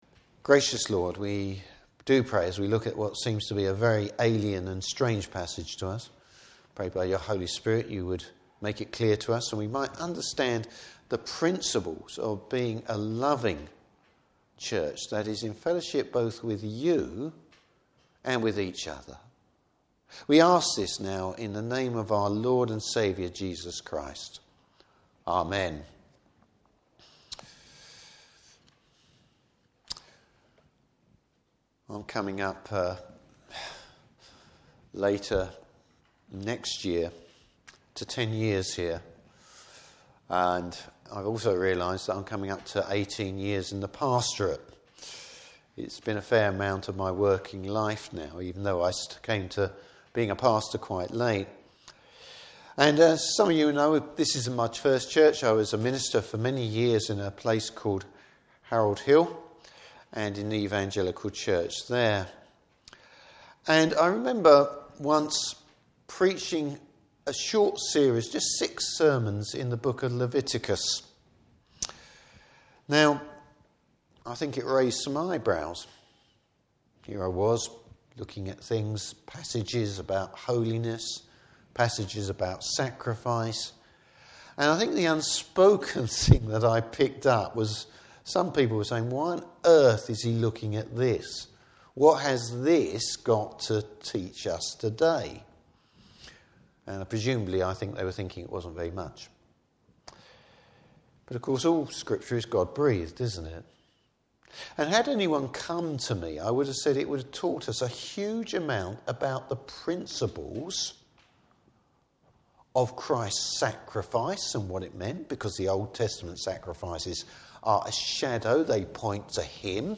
Service Type: Morning Service Just because you have freedom doesn’t mean you have to use it Topics: Christian Love.